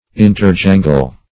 Search Result for " interjangle" : The Collaborative International Dictionary of English v.0.48: Interjangle \In`ter*jan"gle\, v. i. To make a dissonant, discordant noise one with another; to talk or chatter noisily.